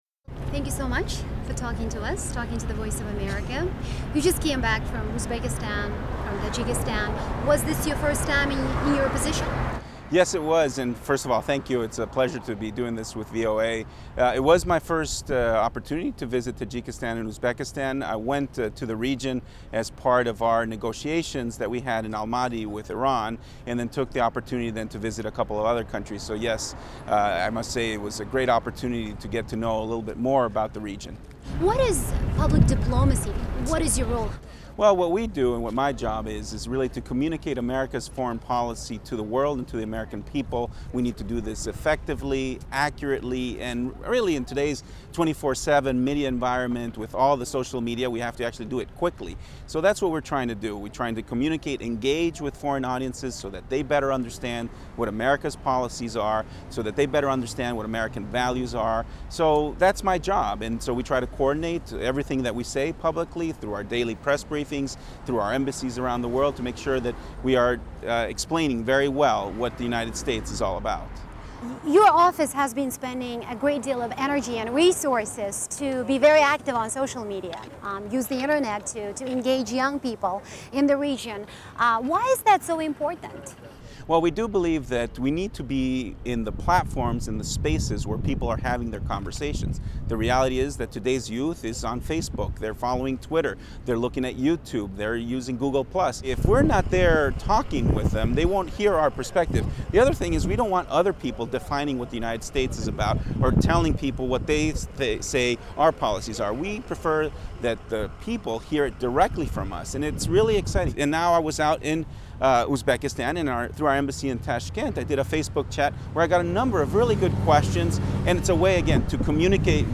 Interview with Assistant Secretary Mike Hammer